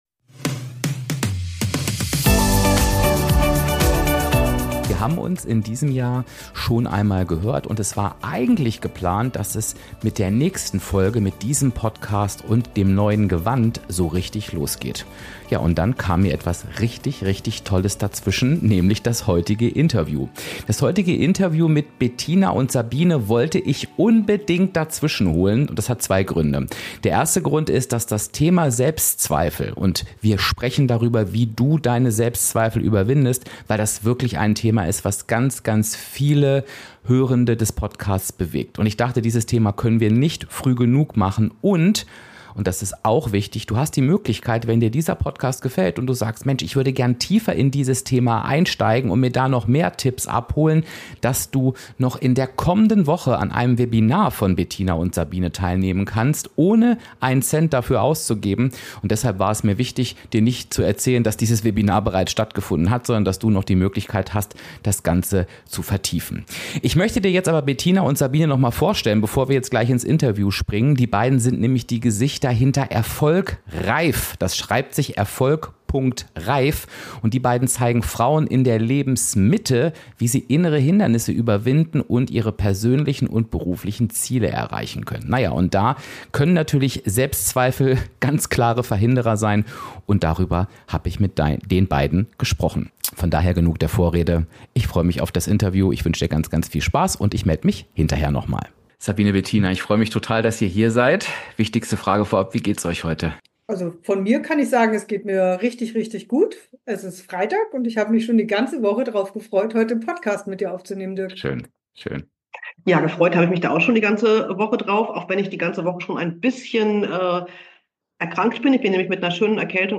- So überwindest du deine Selbstzweifel (Interview mit Erfolg.Reif)